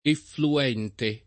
DOP: Dizionario di Ortografia e Pronunzia della lingua italiana
effluire [ efflu- & re ] v.